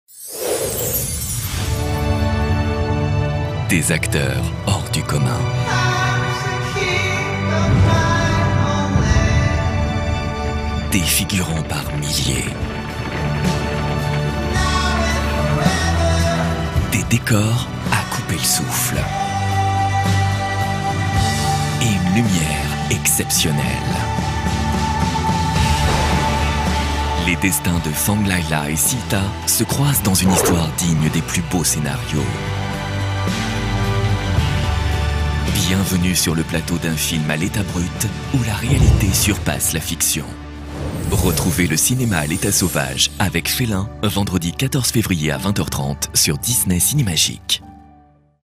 DISNEY CINEMAGIC evenement - Comédien voix off
Genre : voix off.